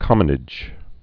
(kŏmə-nĭj)